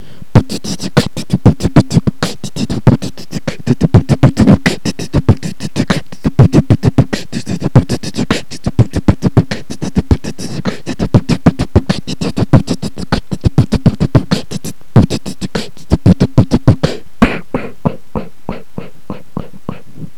Форум российского битбокс портала » Реорганизация форума - РЕСТАВРАЦИЯ » Выкладываем видео / аудио с битбоксом » оцените.
в целом хорошо,четкости бы чуток,да и ритм ,потренируйся с метрононом))а в целом нормально ,МИР)кстате скоко битуешь??) cool biggrin